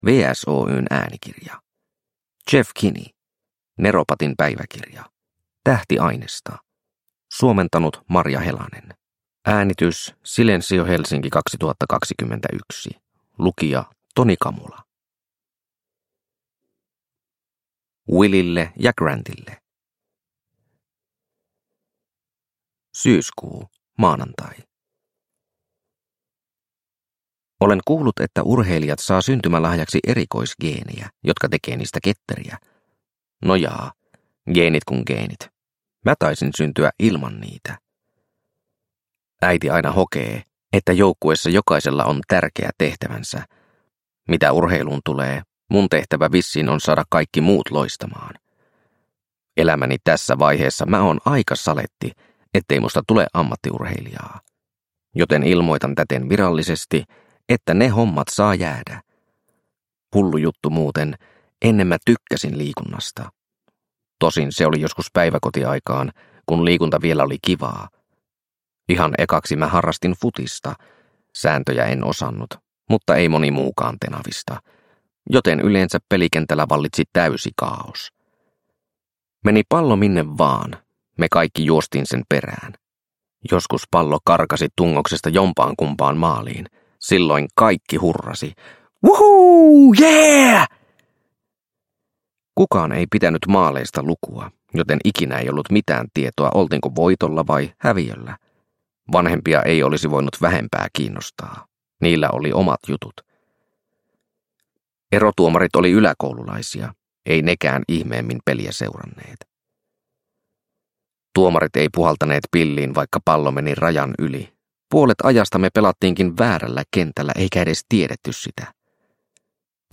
Neropatin päiväkirja: Tähtiainesta – Ljudbok – Laddas ner